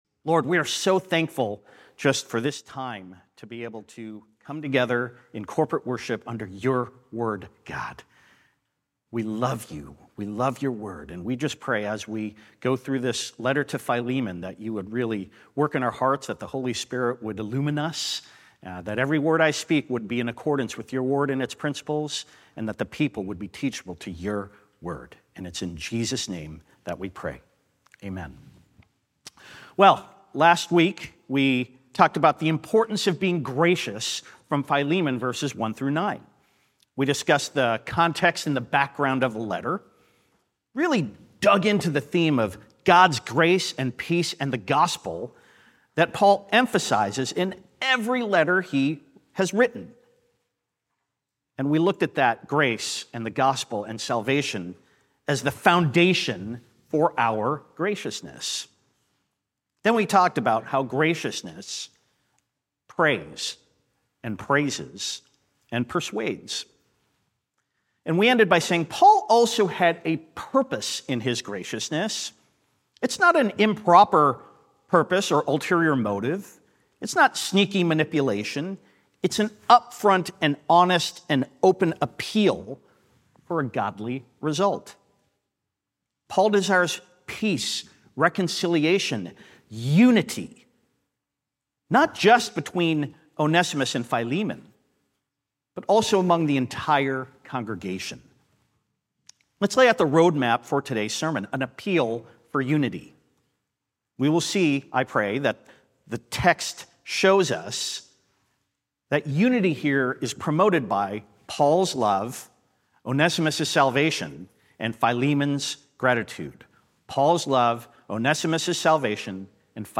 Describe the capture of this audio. occasional guest speakers deliver God's Word at Christ Bible Church's weekly Sunday services.